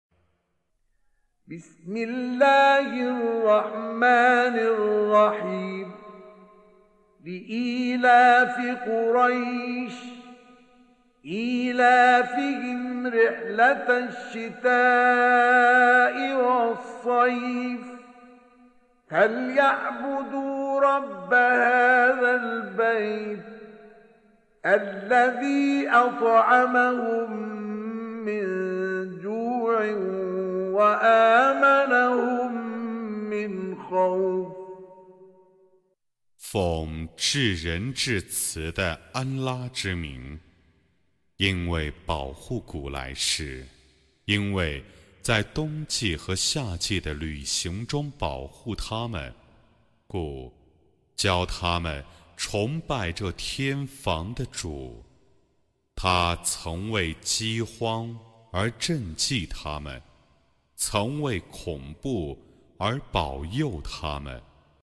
106. Surah Quraish سورة قريش Audio Quran Tarjuman Translation Recitation